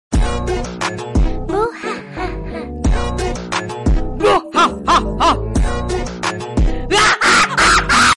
hahahaha🤣🤣 sound effects free download